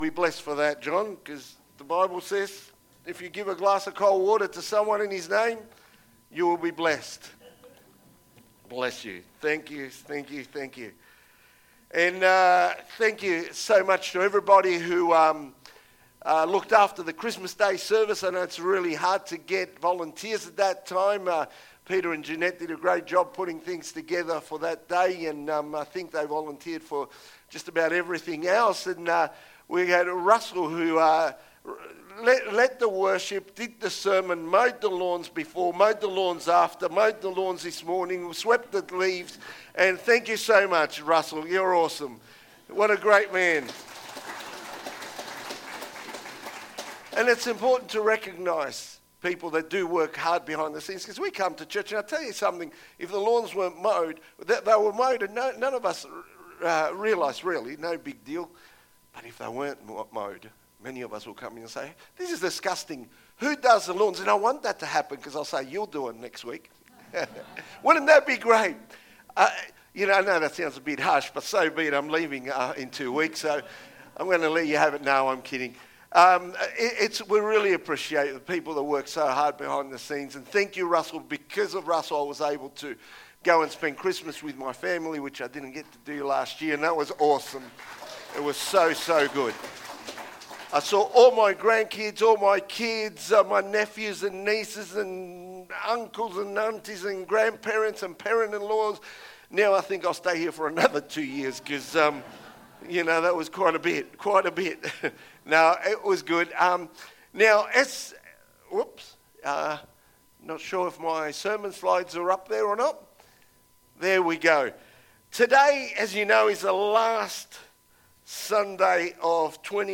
2025 • 40.39 MB Listen to Sermon Download this Sermon Download this Sermon To download this sermon